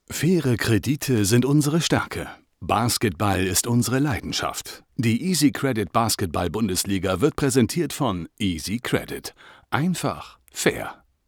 dunkel, sonor, souverän, markant, plakativ, sehr variabel
Mittel plus (35-65)
Commercial (Werbung)